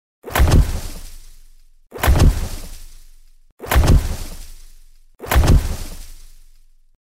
Здесь собраны реалистичные рычания, шаги и крики различных видов древних рептилий.
Звук шагов тираннозавра